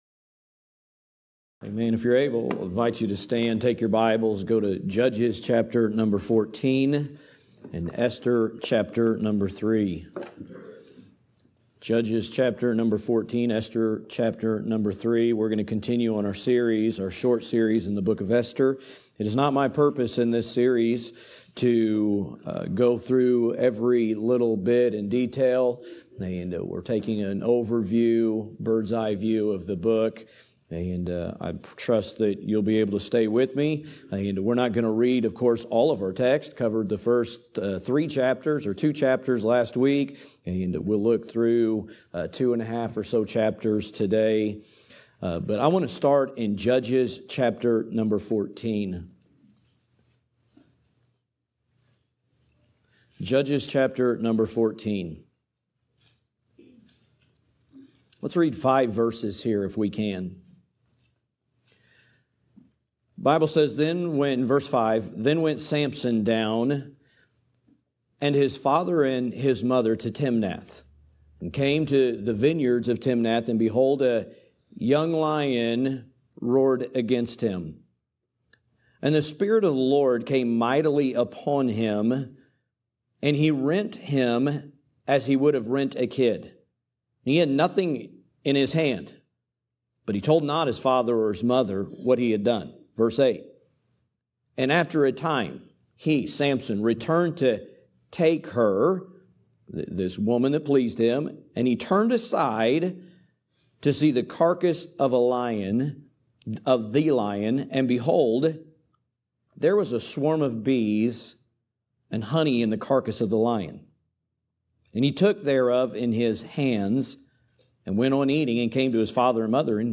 A message from the series "Esther."